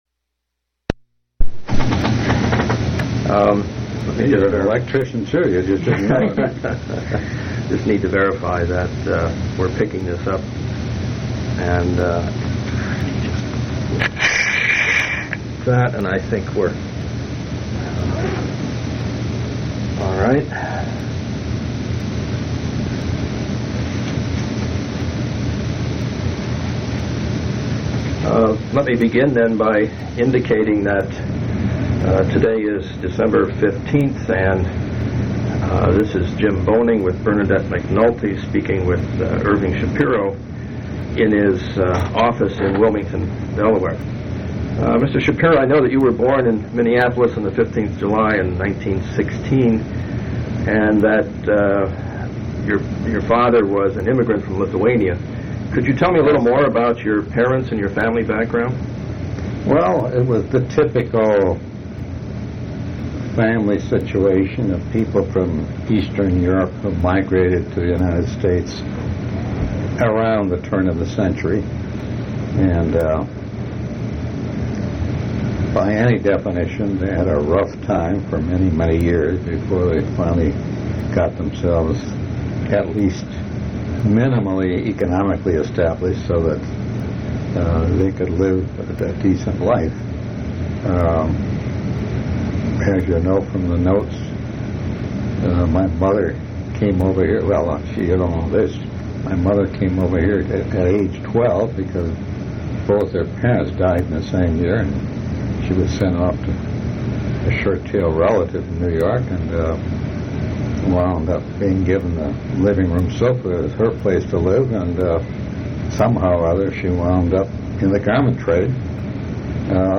Oral history interview with Irving S. Shapiro